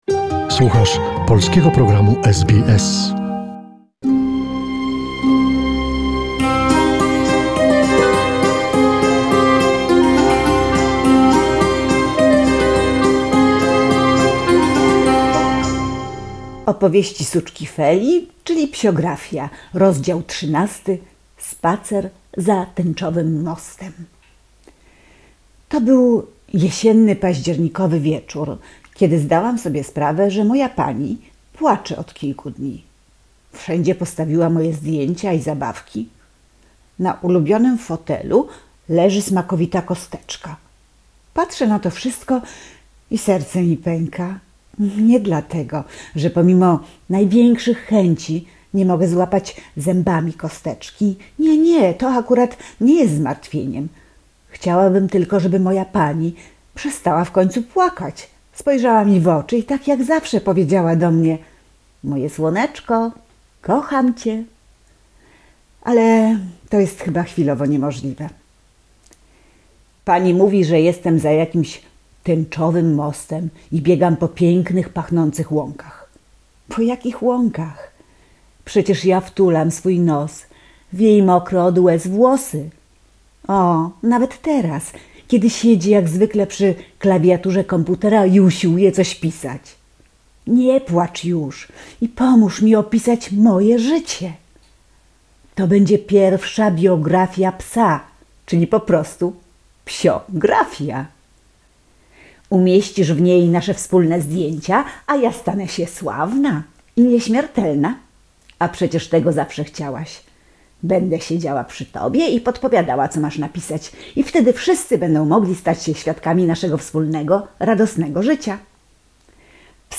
The last episode of the book " Fela's story or psiography" in the interpretation of the author and actress Magdalena Wołłejko.